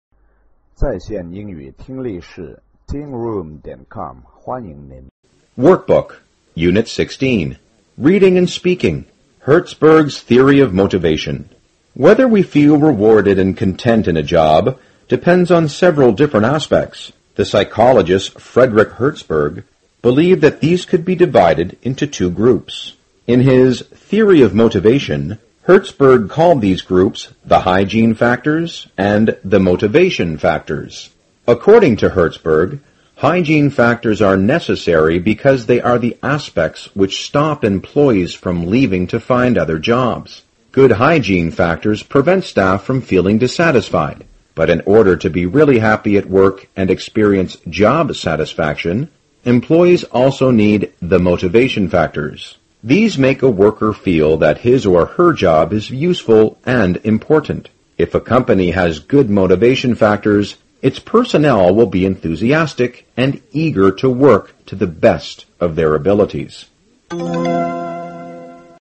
高中英语第三册课本朗读workbook-unit16 听力文件下载—在线英语听力室